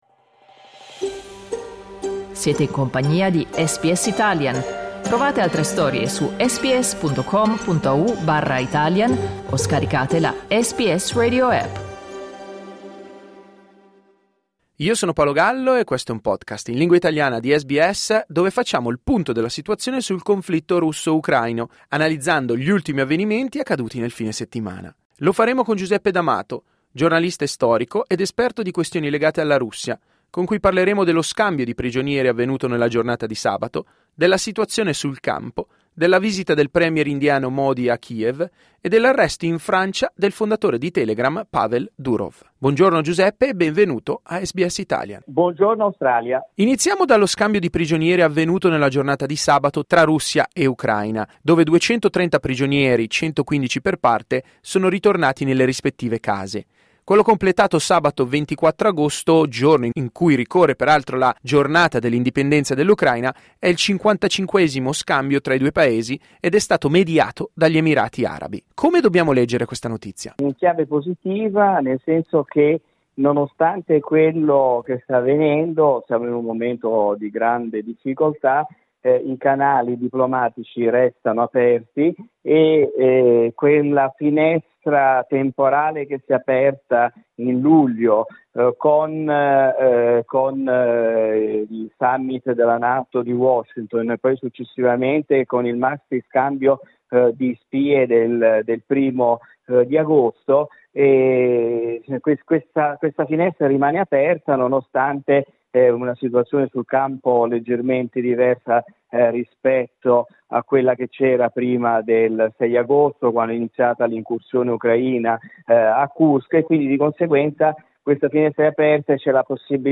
SBS in Italiano